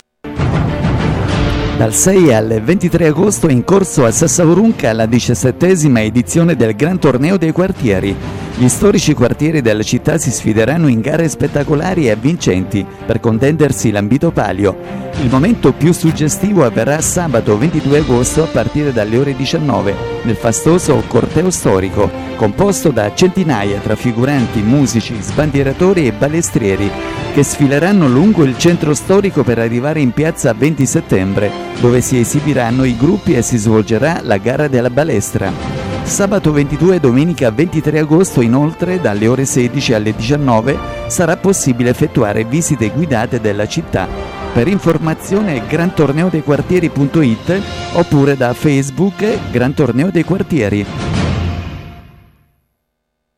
Spot radiofonico - Radio Circuito Solare - XVII Edizione - A.D. 2015 - (
spot-radiofonico-2015.mp3